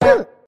tt_s_ara_cfg_toonHit.ogg